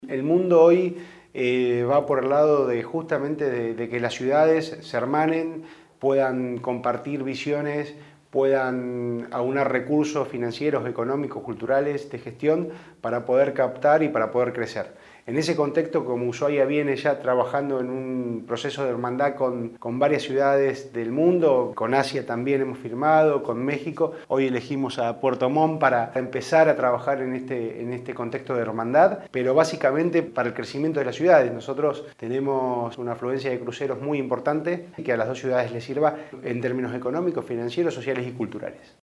Por su parte el Intendente municipal de Ushuaia, Walter Vuoto, se manifestó conforme con la visita de las demás autoridades,ya que se pudo comenzar el trabajo para que ambas ciudades se transformen en socios estratégicos, principalmente en temas relacionados a la industria de cruceros, el intendente Vuoto agregó que  Ushuaia ya viene trabajando en un proceso de hermandad con varias ciudades del mundo, y al estrechar vínculos con Puerto Montt, contribuye al crecimiento y fortalecimiento económico de ambas ciudades.